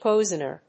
音節pói・son・er 発音記号・読み方
音節poi･son･er発音記号・読み方pɔ́ɪznər